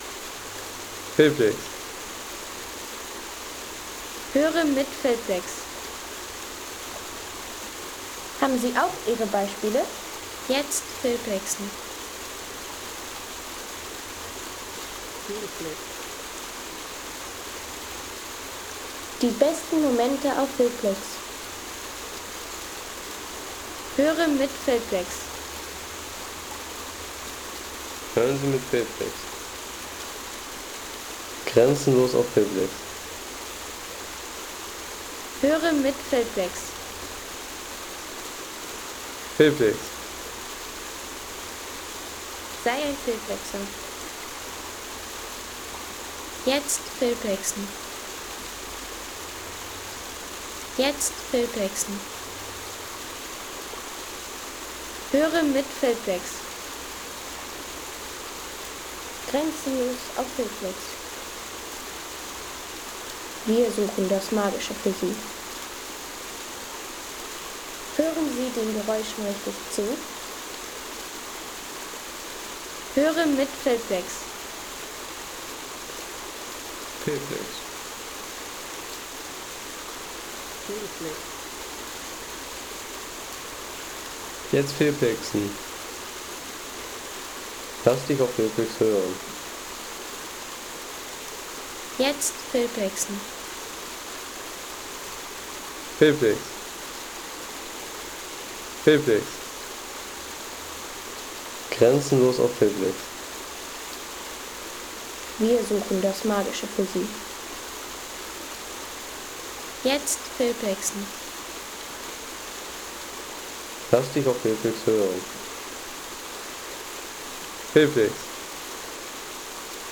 Die besten Soundmomente festhalten. Erholungsgenuss für alle, wie z.B. diese Aufnahme: Wasserfall am Schlossteich
Wasserfall am Schlossteich